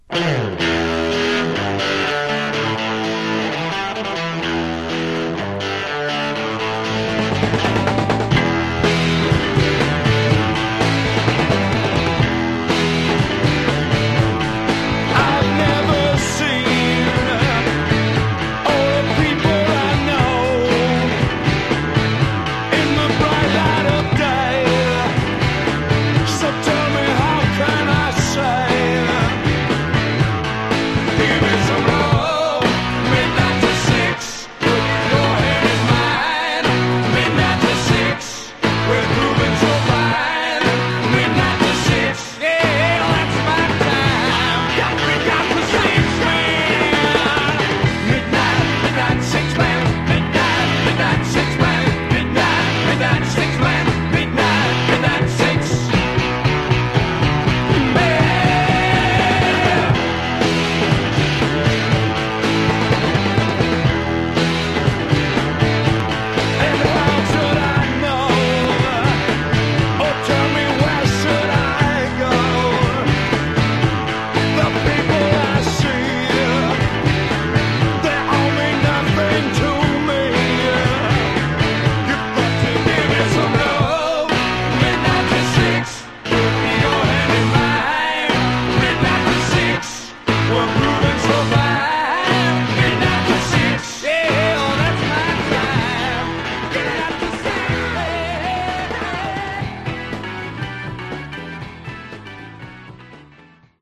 Genre: Garage/Psych
Really great, undiscovered Psychedelic Rock!